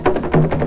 African Drum Sound Effect
Download a high-quality african drum sound effect.
african-drum.mp3